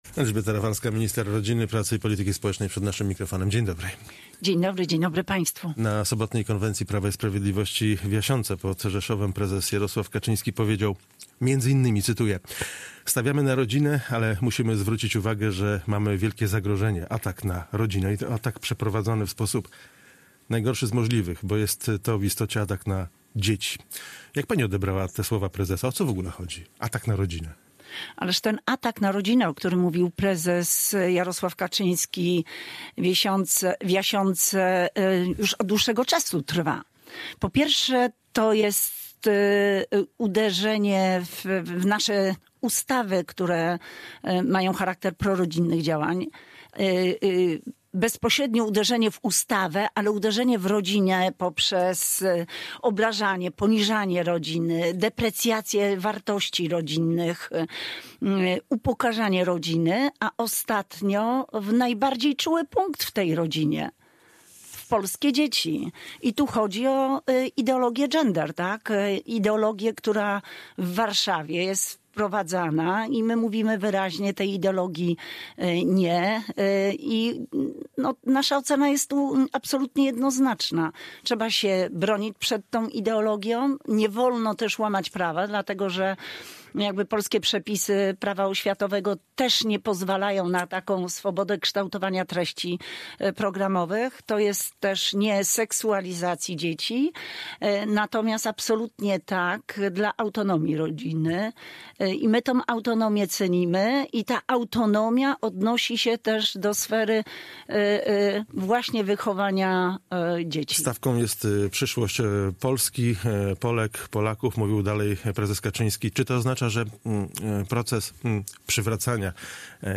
Z minister rodziny, pracy i polityki społecznej rozmawia